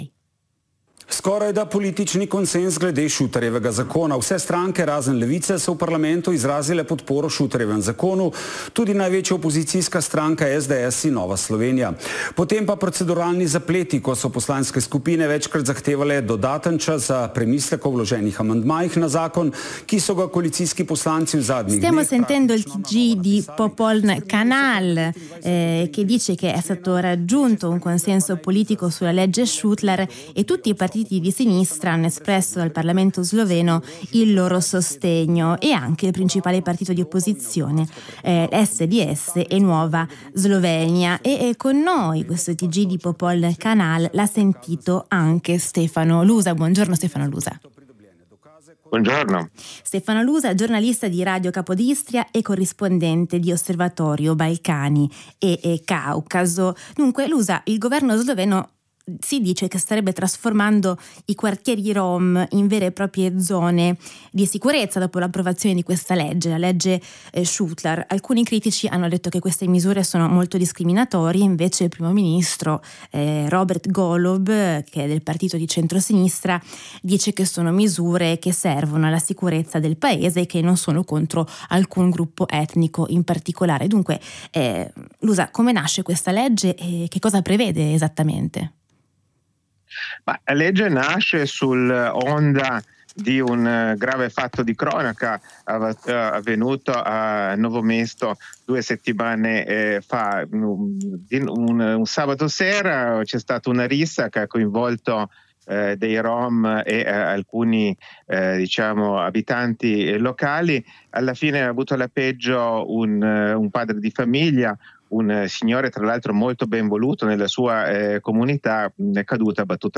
Il governo sloveno ha approvato la cosiddetta “legge Šutar”, un pacchetto di misure urgenti per garantire la sicurezza pubblica. Una risposta alla morte di un uomo, attribuita a un gruppo di rom, che tuttavia rischia di discriminare e peggiorare le condizioni sociali dell’intera comunità rom della Slovenia.  Intervento